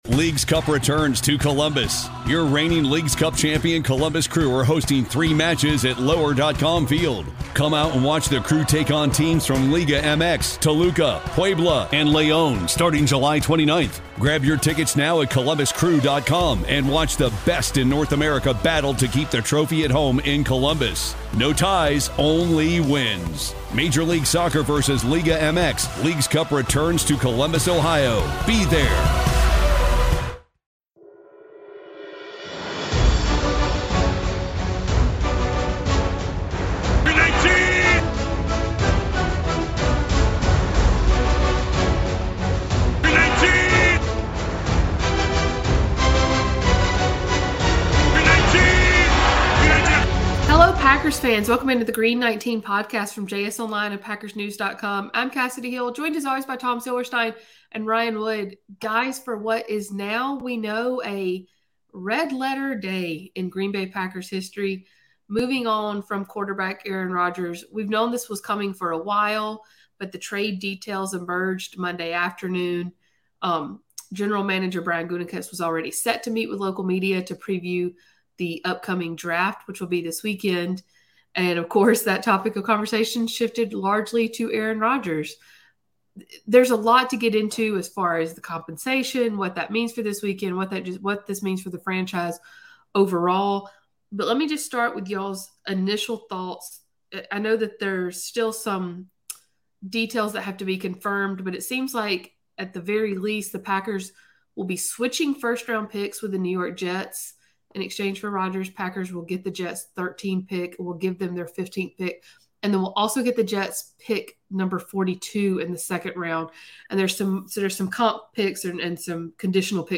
Packers Podcast